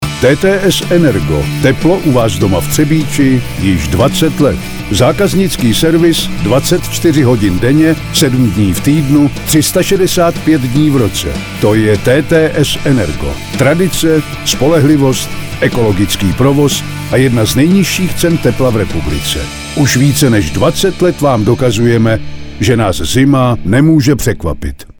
V lednu uslyšíte náš nový rádiový spot
Od 7. do 31. ledna na ČRo Vysočina (Region) a Hitrádiu Vysočina poběží náš nový rozhlasový spot, který namluvil známý herec Pavel Rímský.